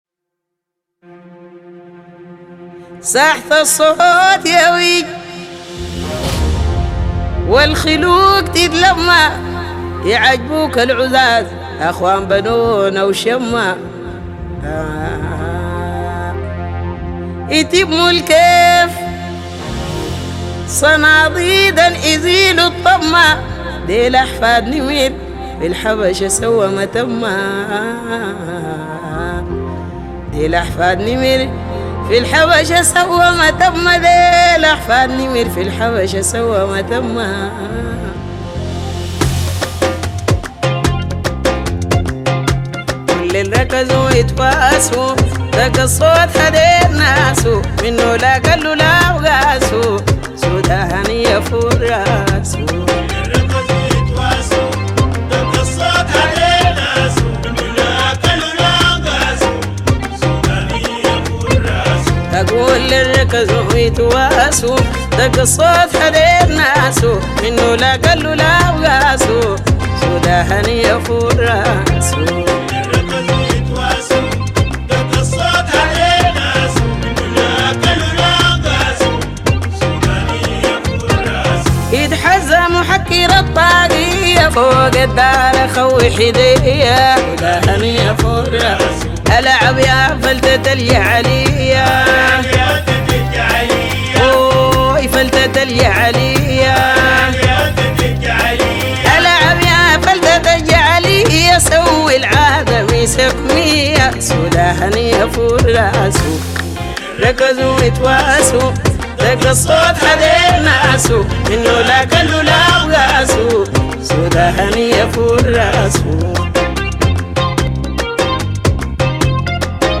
اغاني سودانية